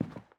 Sounds / sfx / Footsteps / Carpet
Carpet-04.wav